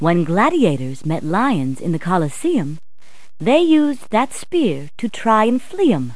Tour Guide voice clip in Mario is Missing! CD-ROM Deluxe